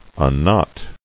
[un·knot]